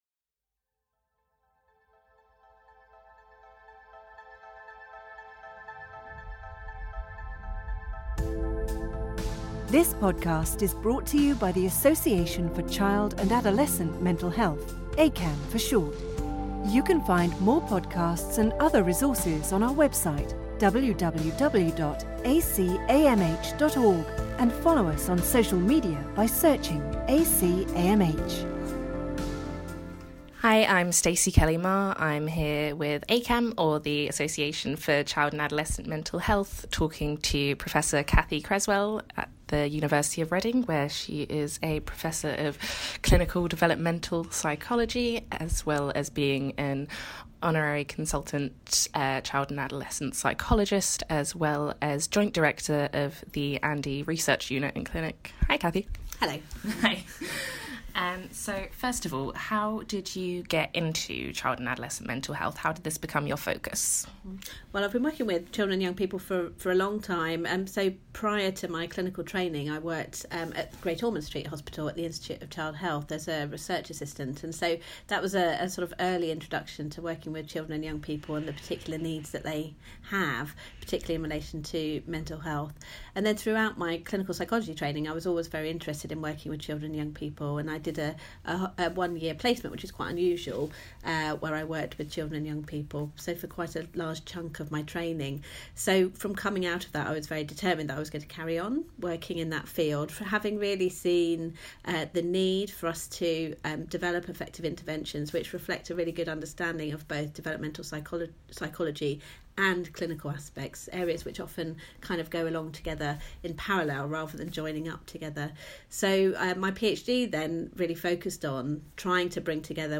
In Conversation... Anxiety